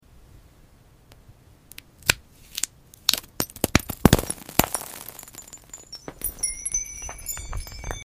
Peeling a Crystal Onion sound effects free download